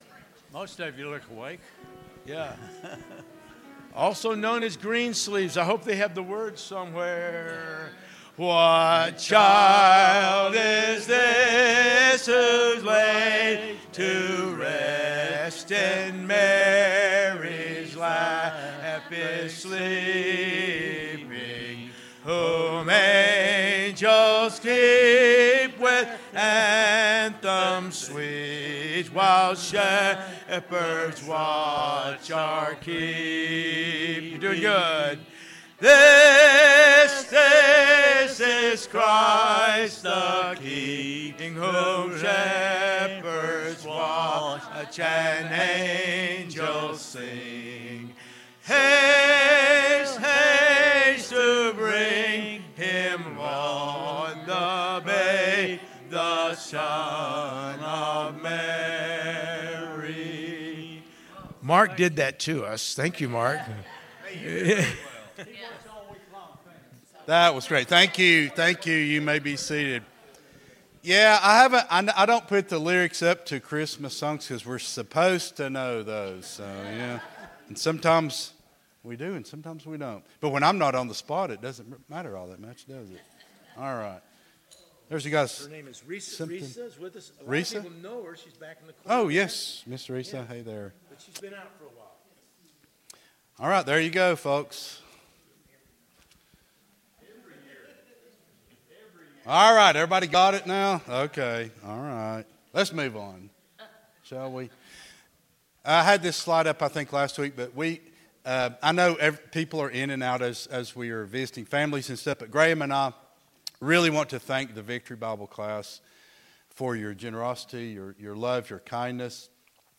Sunday School Lesson
at Buffalo Ridge Baptist Church in Gray, Tn.